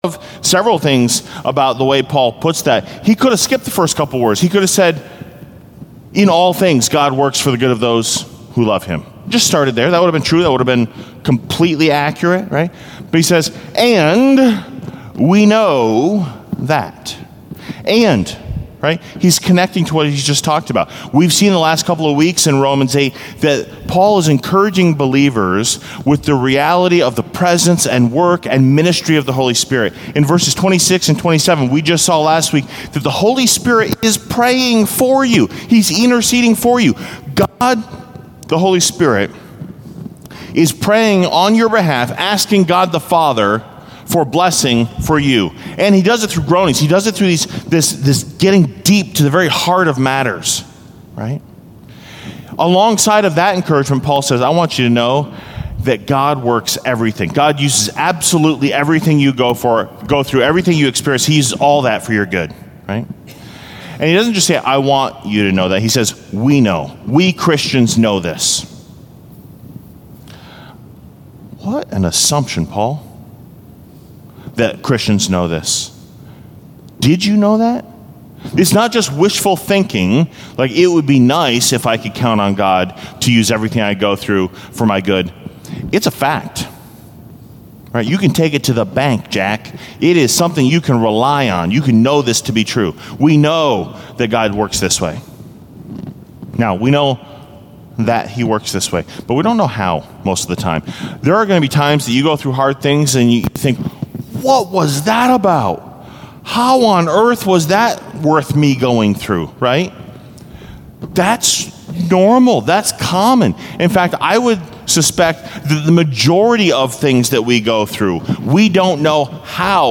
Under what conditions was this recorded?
Due to technical difficulties, the first minute or so of audio recording was missed.